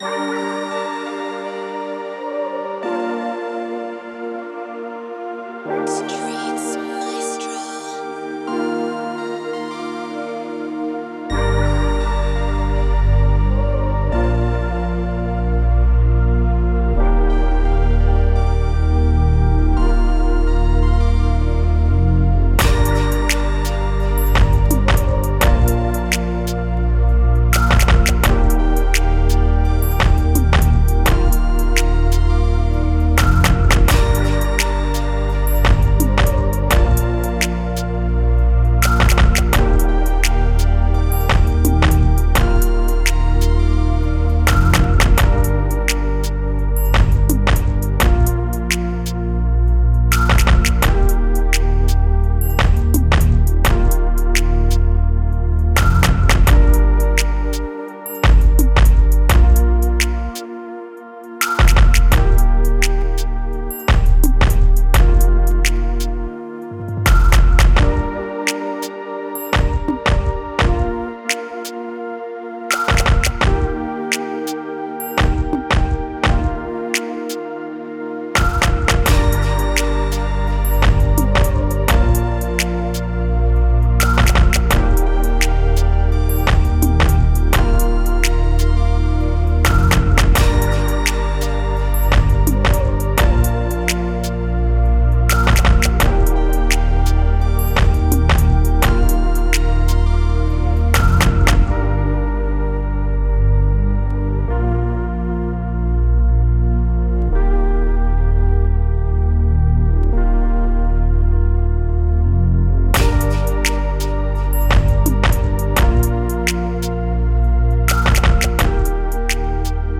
Moods: laid back, intimate, mellow
Genre: Sexy Drill
Tempo: 170